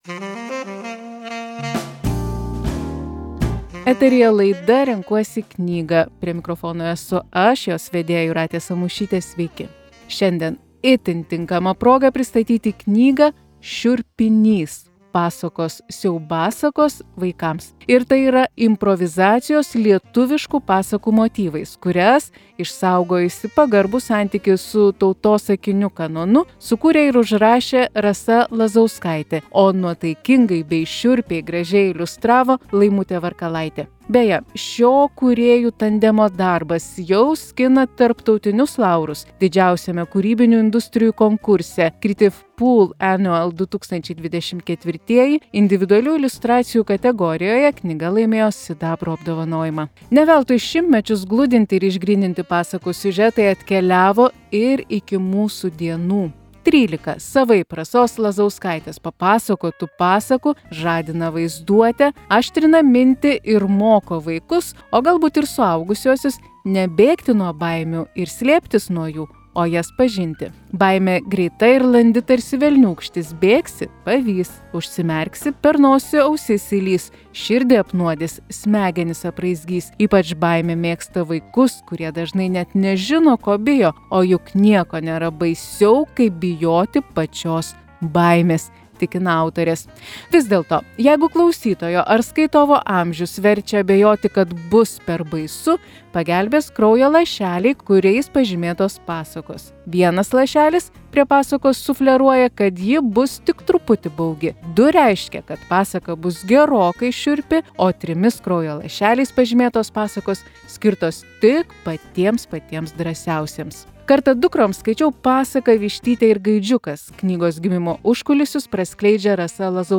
Knygos apžvalga.